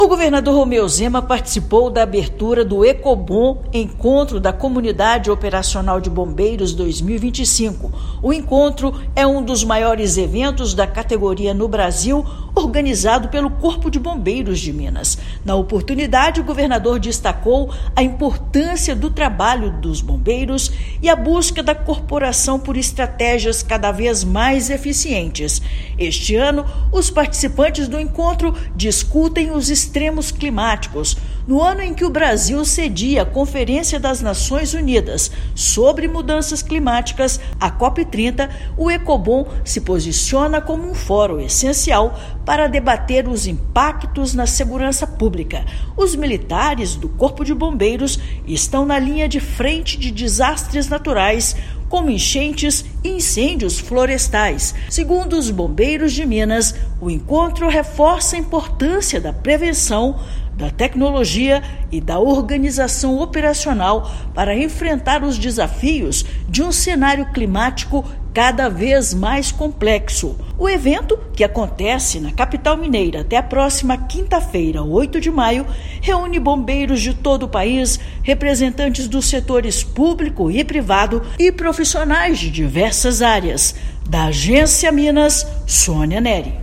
Ecobom 2025 reúne autoridades governamentais, setor privado e instituições de ensino na pauta da prevenção de desastres. Ouça matéria de rádio.